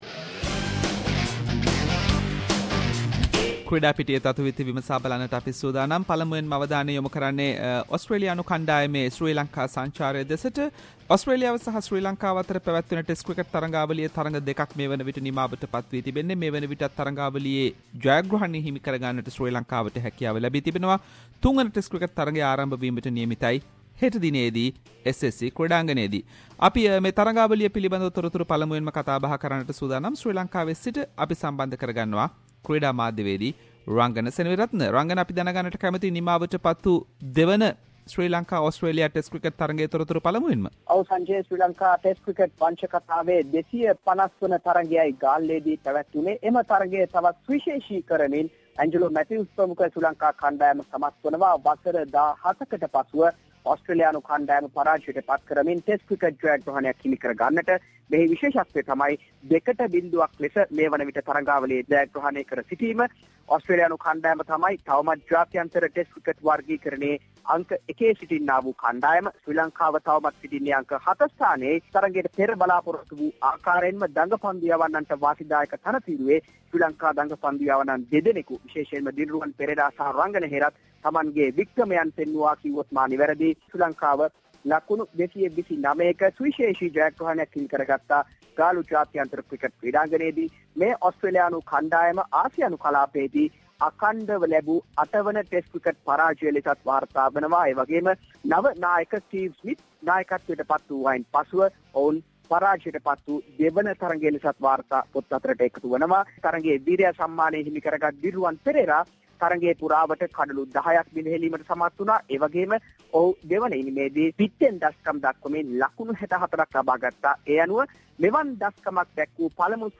Weekly Sports wrap – Australia face “tough and tight” selection for third test